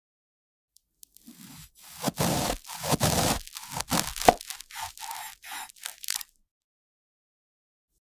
Lava cake cut open with sound effects free download
Lava cake cut open with ASMR-style molten core